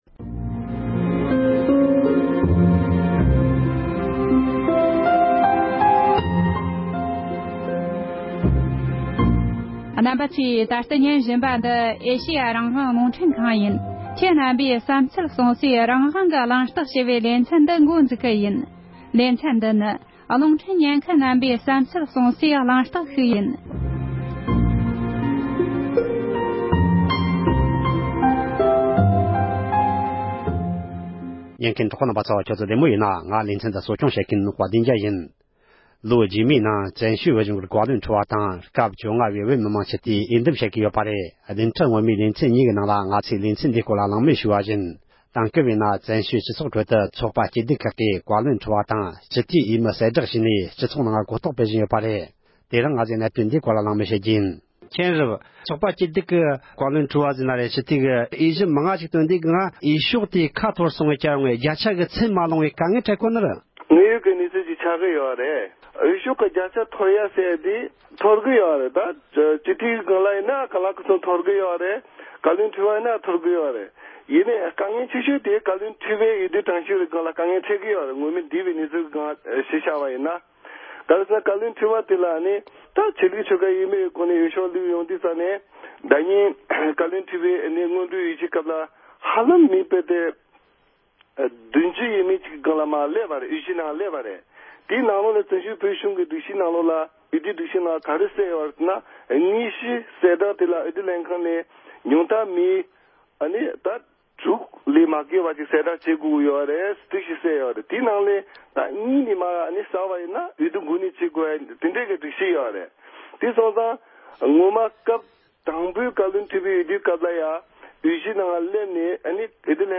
འབྱུང་འགྱུར་བཀའ་བློན་ཁྲི་པའི་འོས་འདེམས་ཐད་གླེང་མོལ།